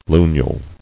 [lu·nule]